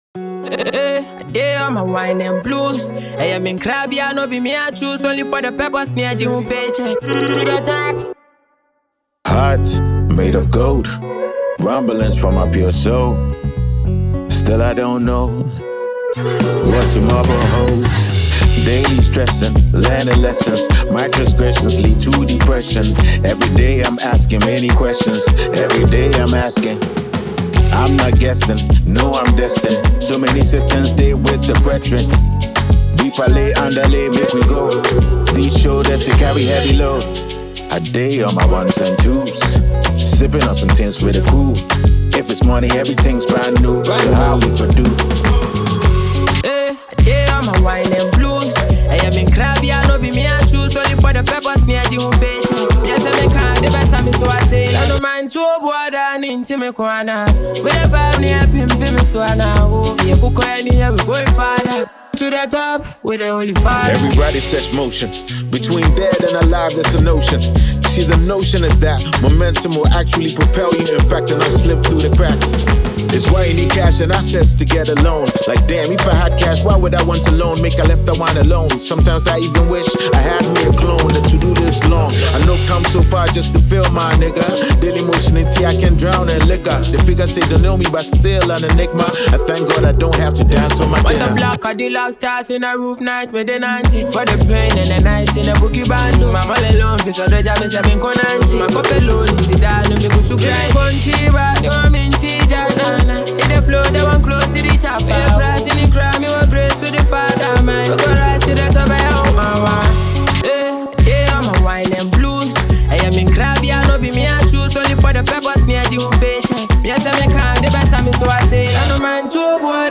Highly-rated Ghanaian rapper